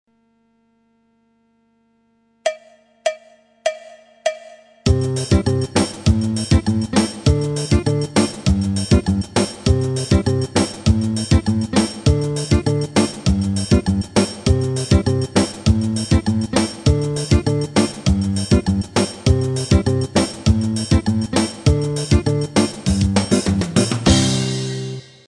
Il se joue quant à lui d'une manière assez rapide avec triangle, zabumba, shaker.
Figure guitare baião 1 avec section rythmique.